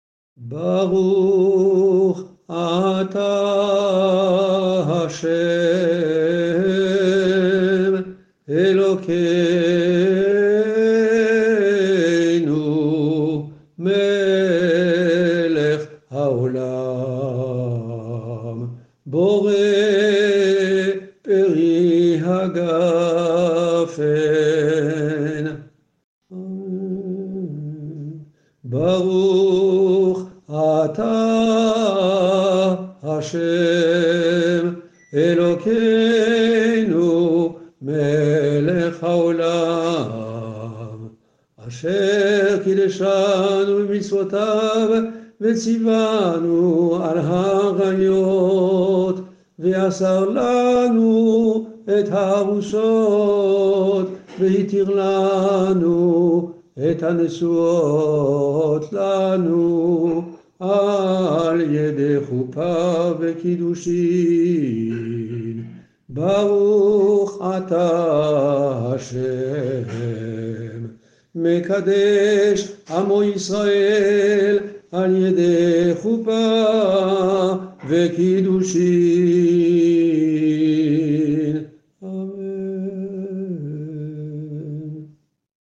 Bénédiction des fiancés sous la ḥouppa (rite ashkénaze